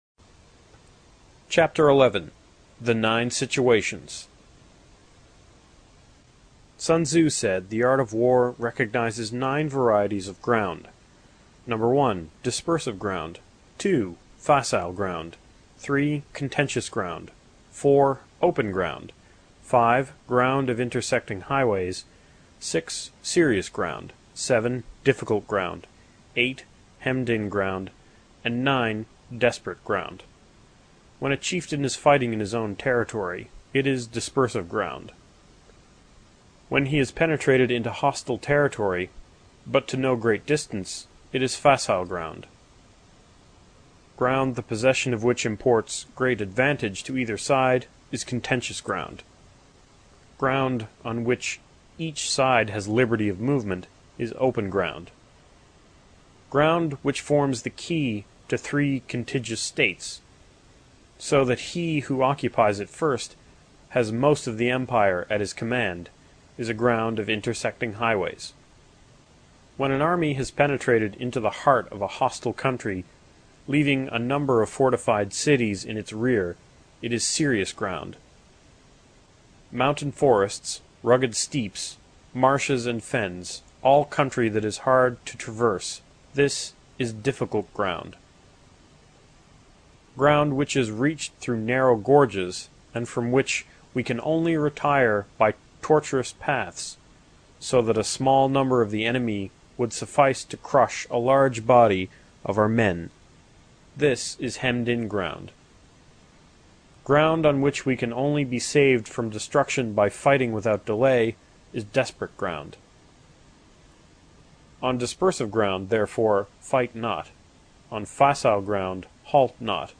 有声读物《孙子兵法》第62期:第十一章 九地(1) 听力文件下载—在线英语听力室